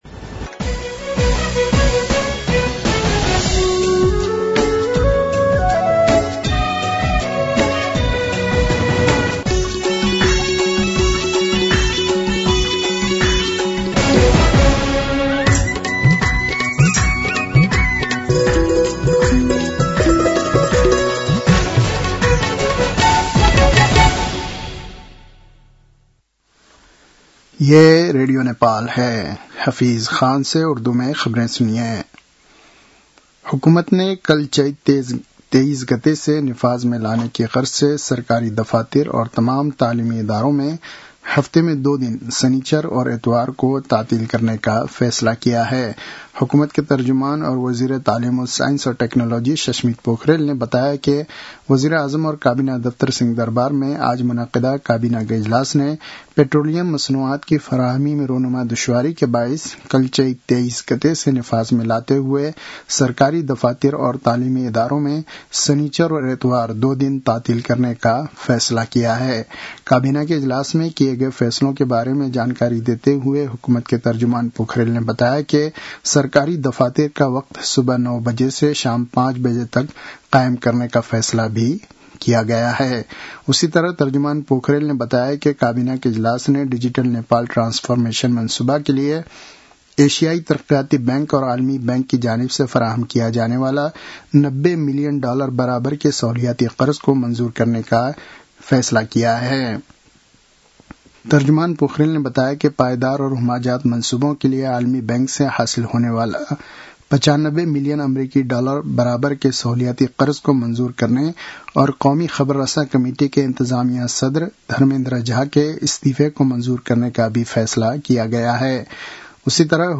उर्दु भाषामा समाचार : २२ चैत , २०८२